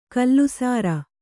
♪ kallusāra